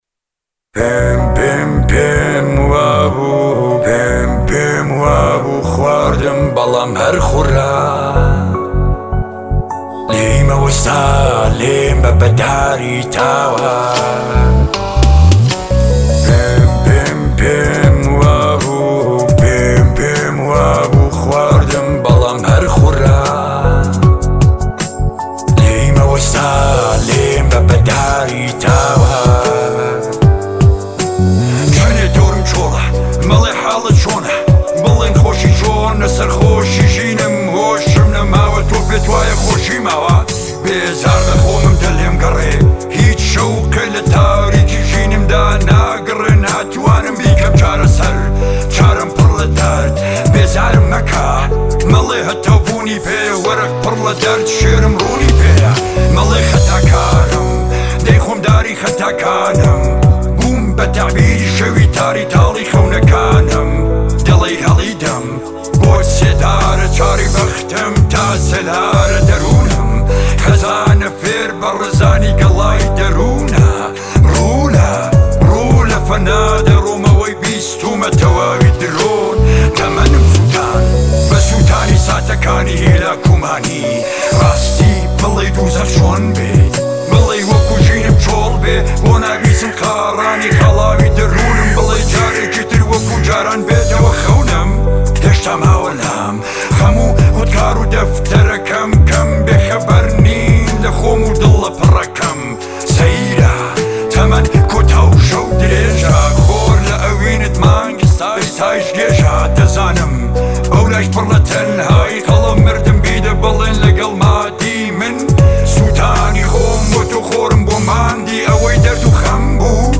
آهنگ کردی غمگین